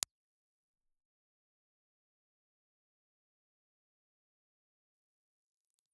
Condenser
Cardioid
Impulse Response file of the Sony C450 in "M" position.
Sony_C450_M_IR.wav